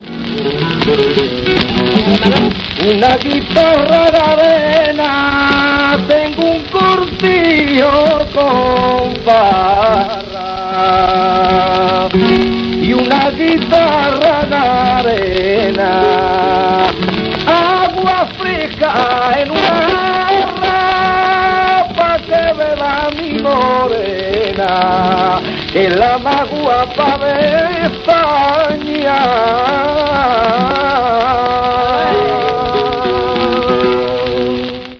guitarra